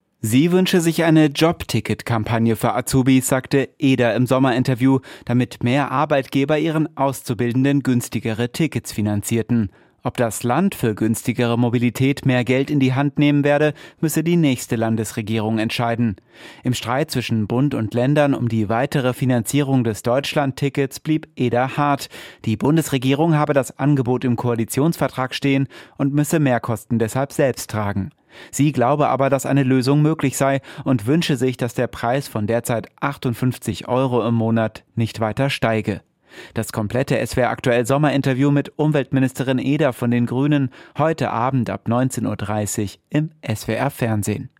Umweltministerin Katrin Eder (Grüne)